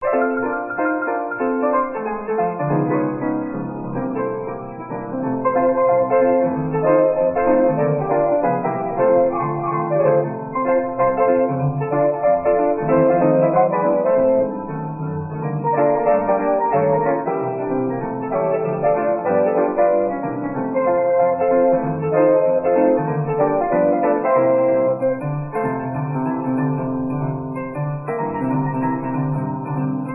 * CREMONA STYLES G,K & J NICKELODOEN MUSIC